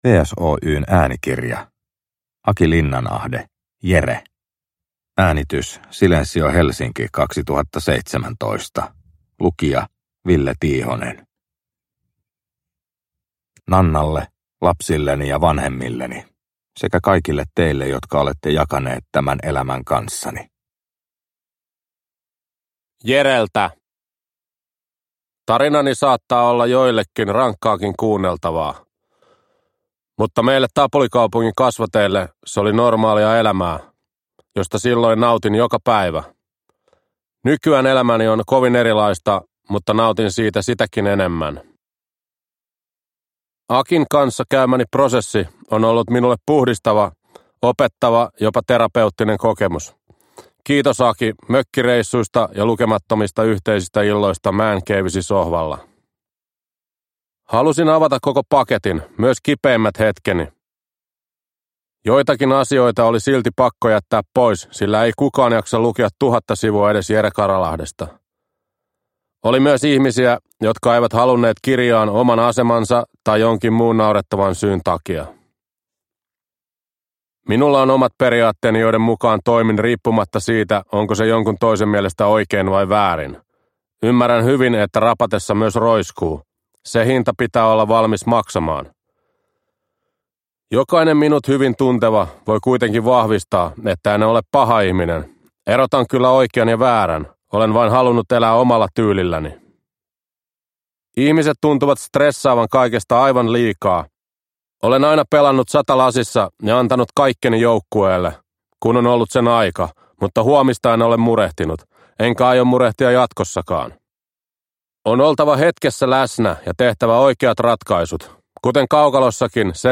Jere – Ljudbok – Laddas ner